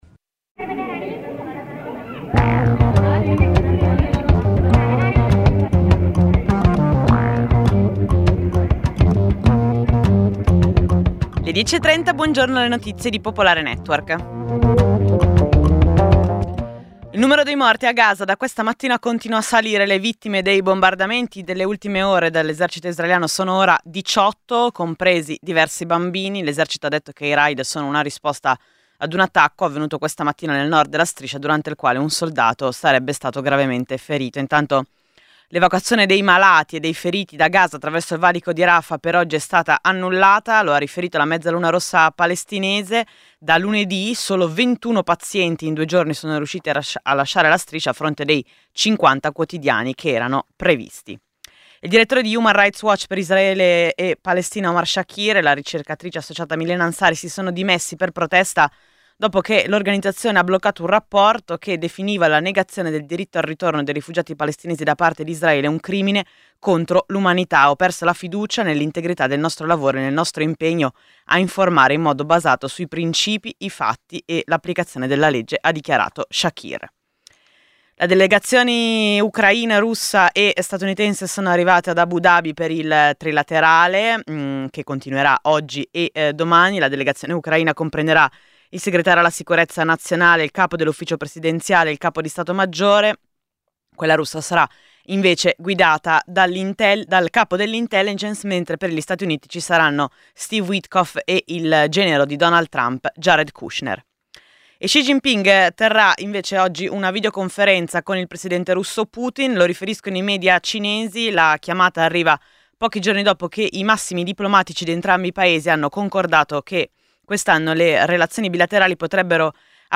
Giornale radio Nazionale